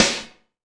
SNARE 020.wav